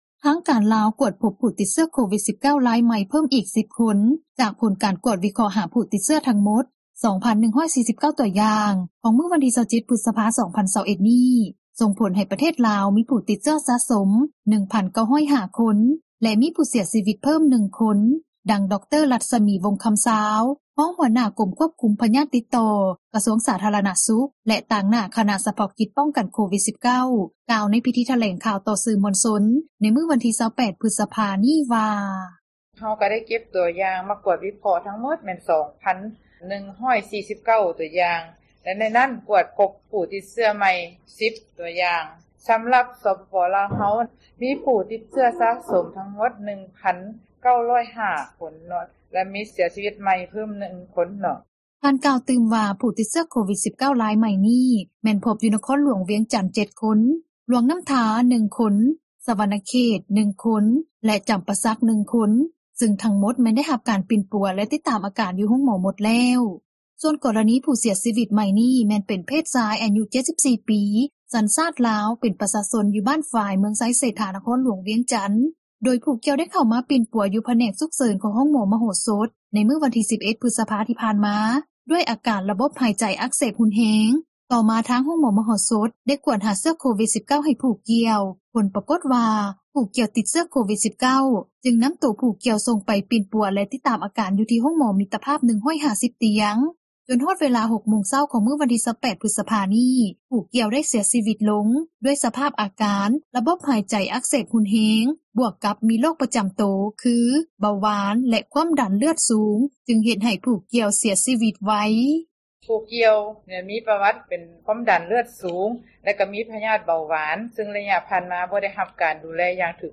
ທາງການລາວ ກວດພົບຜູ້ຕິດເຊື້ອໂຄວິດ-19 ຣາຍໃໝ່ເພີ່ມອີກ 10 ຄົນ ຈາກ ຜົນການກວດວິເຄາະ ຫາຜູ້ຕິດເຊື້ອ ທັງໝົດ 2,149 ຕົວຢ່າງ ຂອງມື້ວັນທີ 27 ພຶສພາ 2021 ນີ້ ສົ່ງຜົນໃຫ້ປະເທດລາວ ມີຜູ້ຕິດເຊື້ອສະສົມ 1,905 ຄົນ ແລະມີຜູ້ເສັຽຊີວິດເພີ່ມ 1 ຄົນ, ດັ່ງ ດຣ. ລັດສະໝີ ວົງຄໍາຊາວ ຮອງຫົວໜ້າກົມຄວບຄຸມ ພຍາດຕິດຕໍ່ ກະຊວງສາທາຣະນະສຸຂ ແລະ ຕາງໜ້າຄະນະສະເພາະກິຈ ປ້ອງກັນໂຄວິດ-19 ກ່າວໃນພິທີຖແລງຂ່າວ ຕໍ່ສື່ມວນຊົນ ໃນມື້ວັນທີ 28 ພຶສພາ ນີ້ວ່າ: